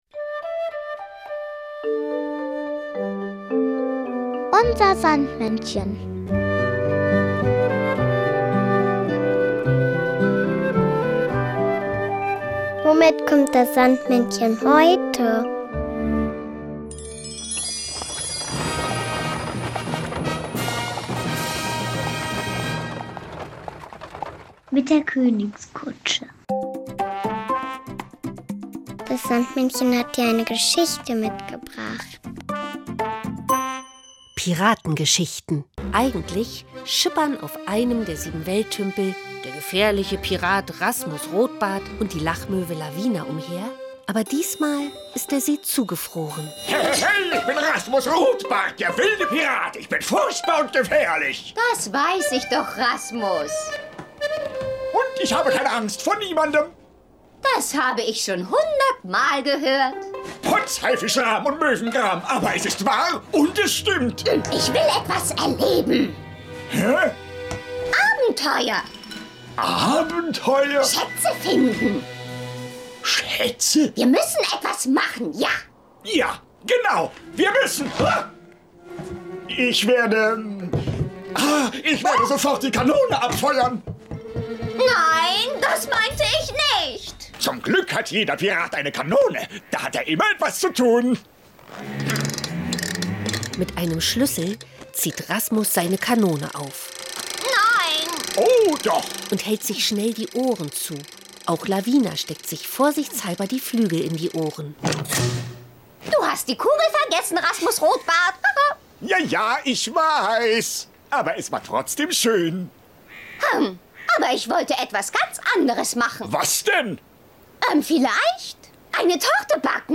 Kinderlied